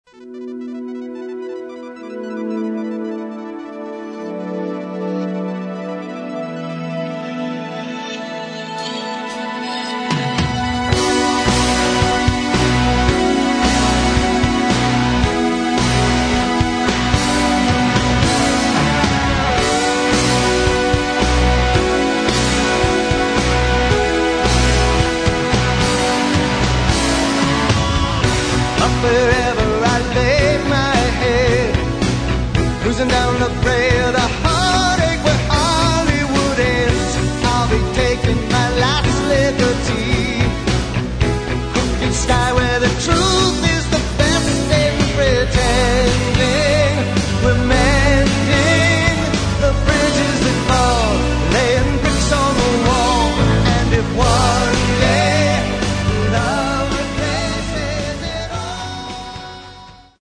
Рок
Альбом очень интересный, прекрасно записаный, отличный звук.